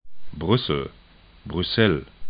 'brʏsl